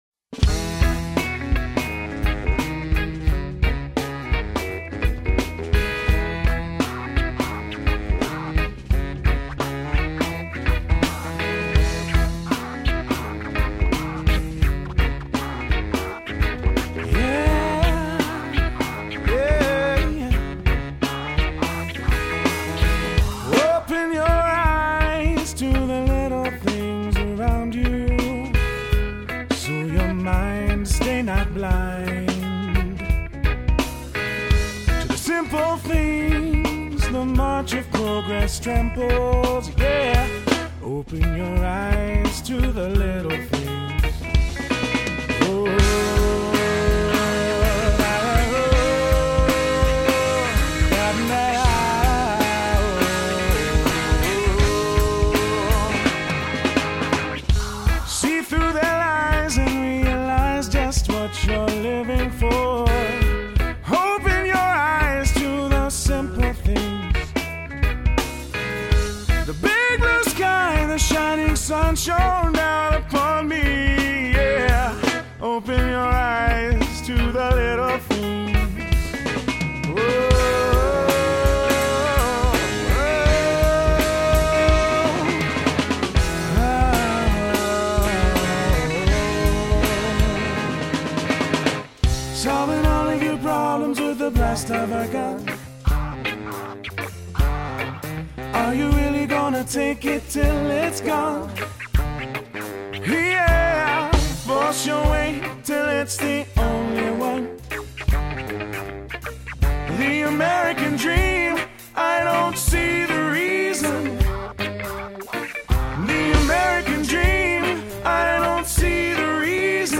a South Texas ska and punk band.